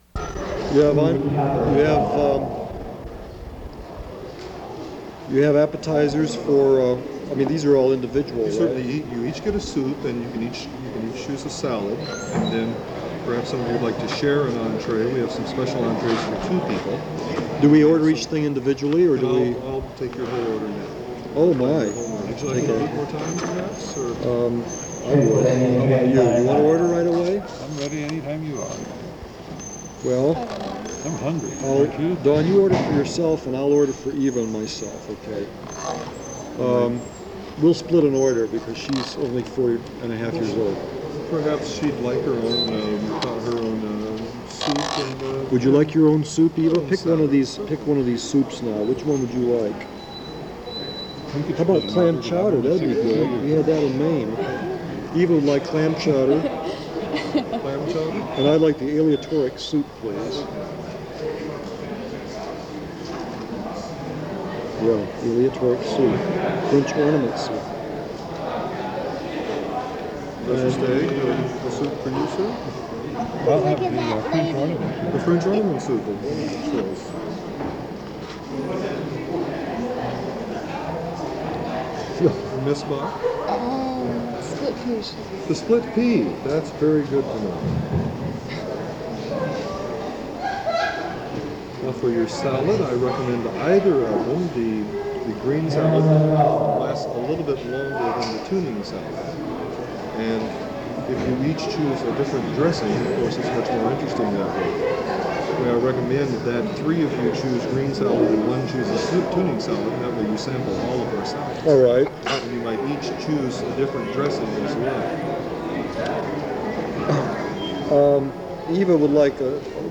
Cafe_Concert_3_December_1982.mp3